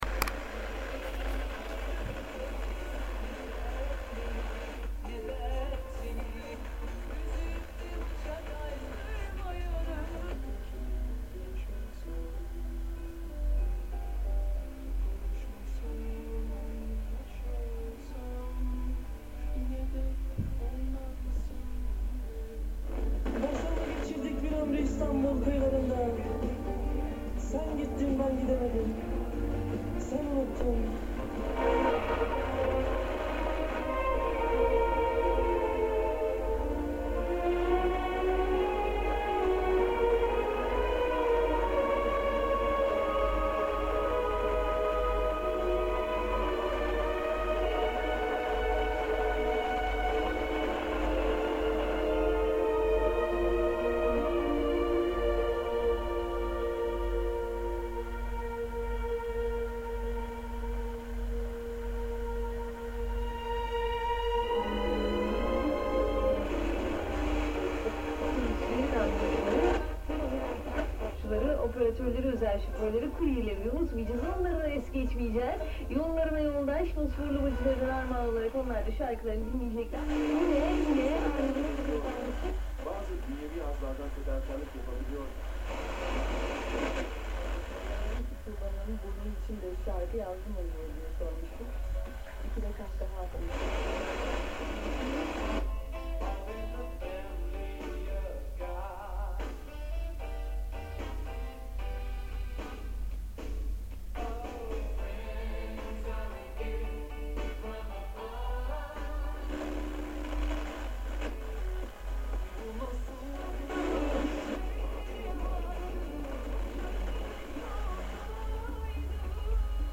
Kadıköy, August 2009, afternoon at an apartment flat.
Here’s a six minute potpourri recording of all the FM radio channels audible at Kadıköy rolled slowly from the lowest k